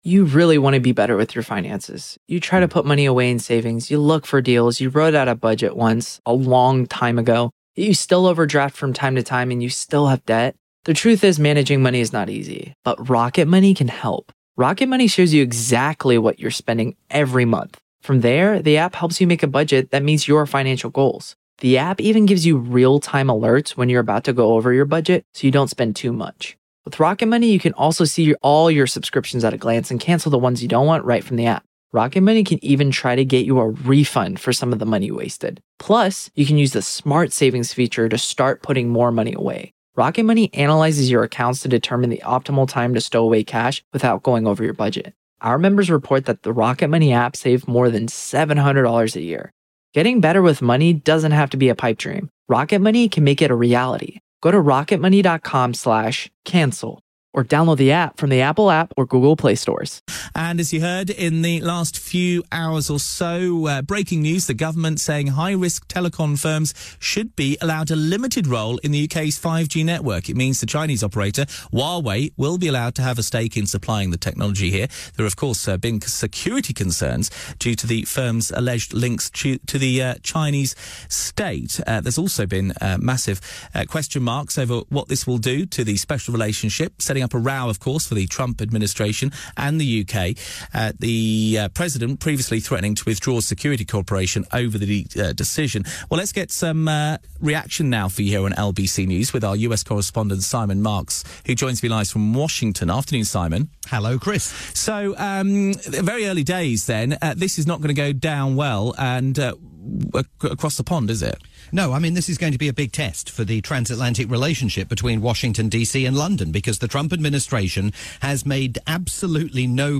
live report on the US reaction to Boris Johnson's decision to allow Huawei to build part of the UK's 5G infrastructure.